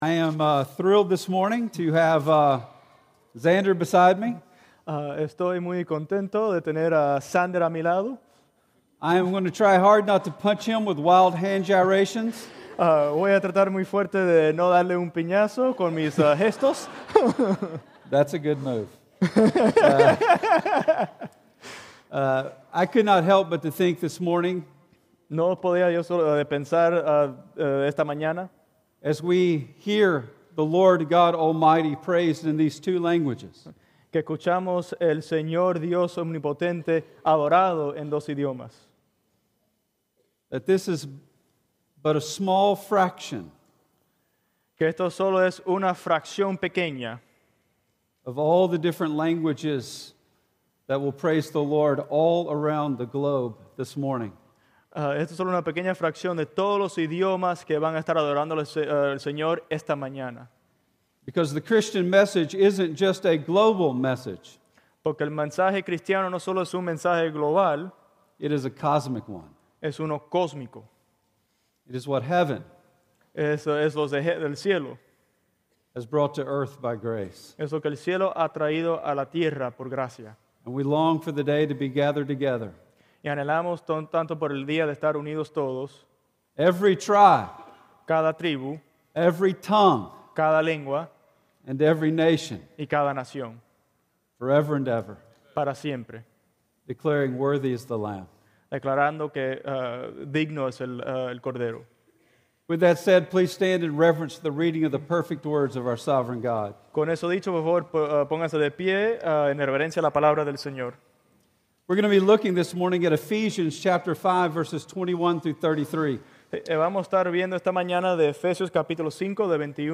In today's combined Spanish language and English language service we see Paul radical Christ-centered view of family.
Sermon Audio